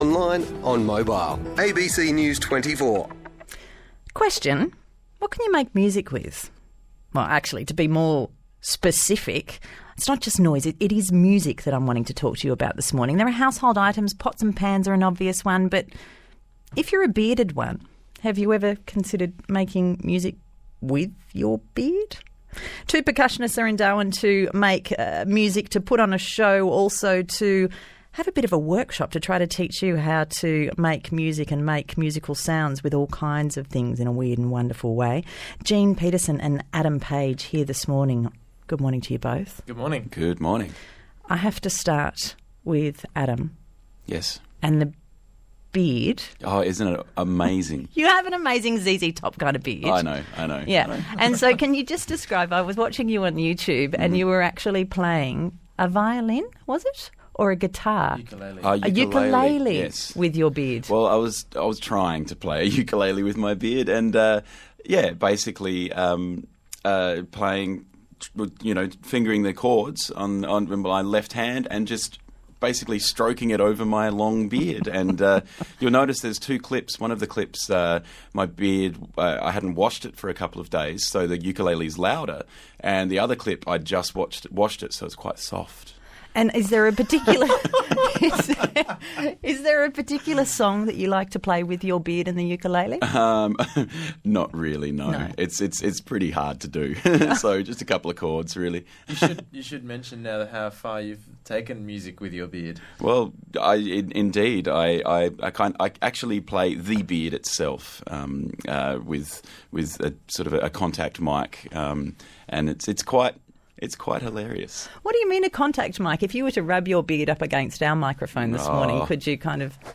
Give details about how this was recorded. They finished off the session with a jam. loop-the-loop-abc-breakfast-radio-darwin.mp3